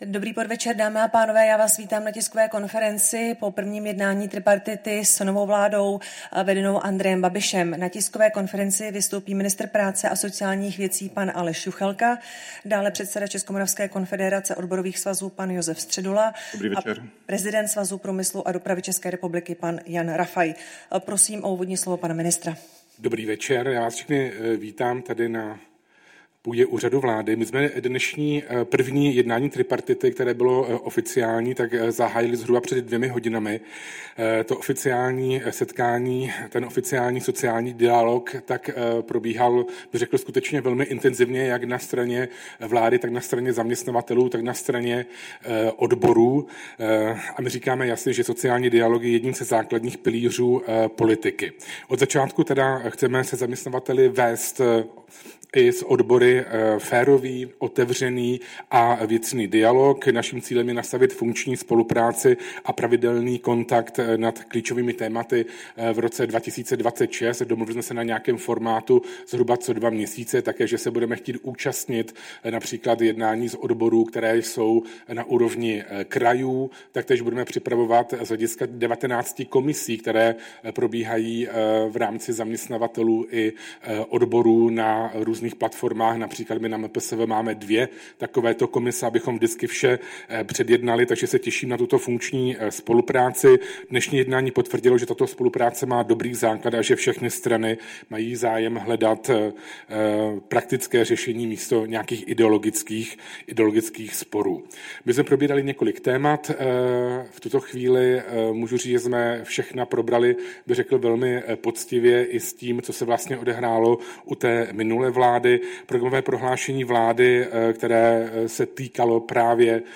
Tisková konference po plenární schůzi Rady hospodářské a sociální dohody ČR, 12. ledna 2026